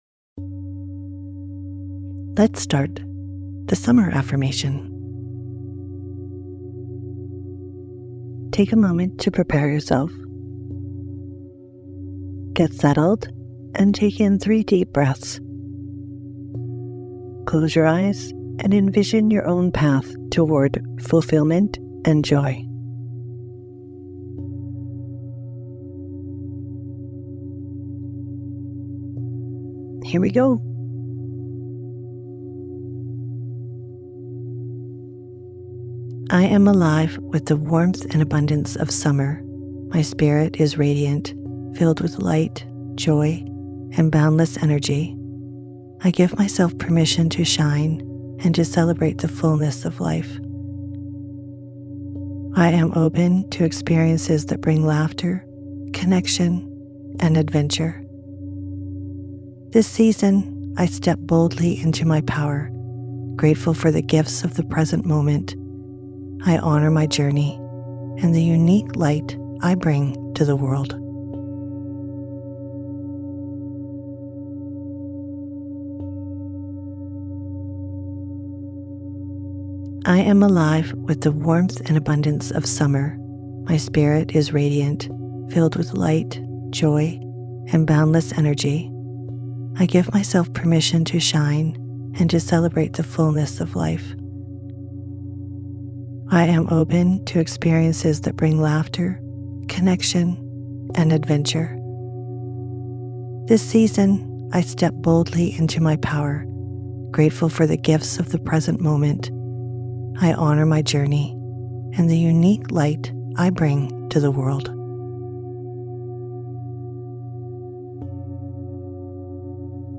Sleep versions feature the affirmation repeated three times, creating repetition for deeper impact and greater benefits.